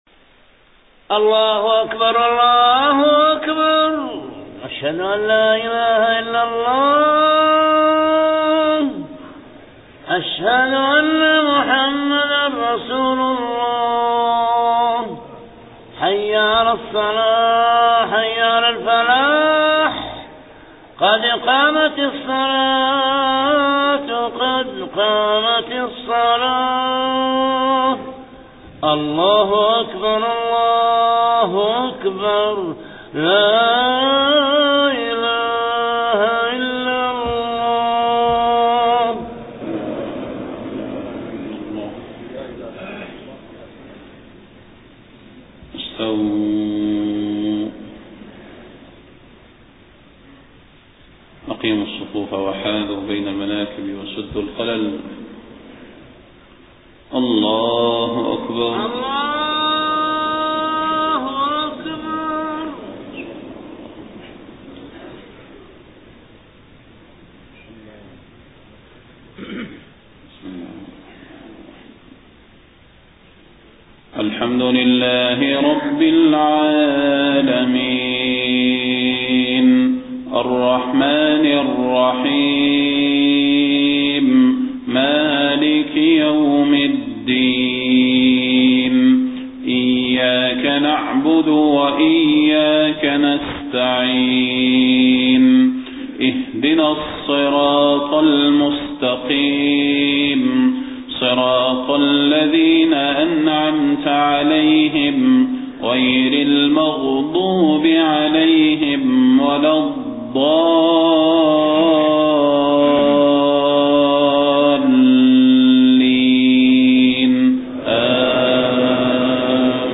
صلاة الفجر 24 صفر 1431هـ من سورة غافر 51-68 > 1431 🕌 > الفروض - تلاوات الحرمين